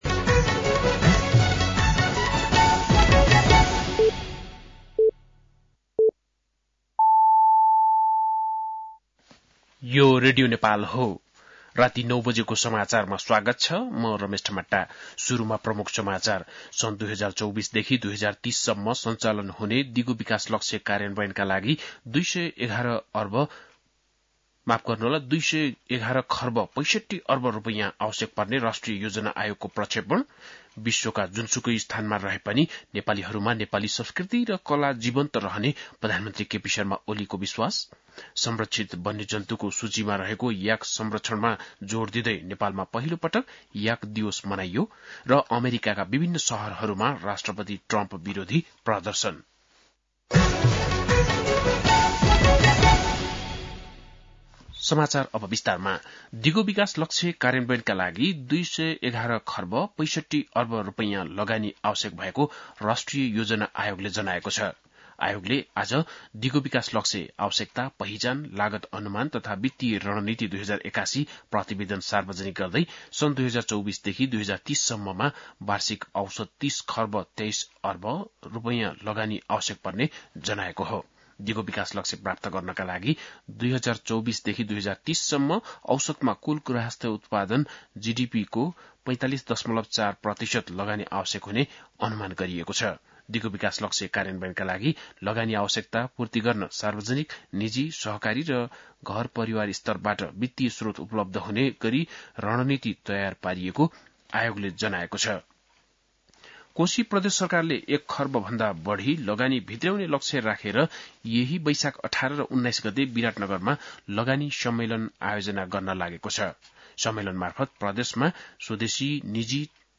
बेलुकी ९ बजेको नेपाली समाचार : ७ वैशाख , २०८२
9-pm-nepali-news1-7.mp3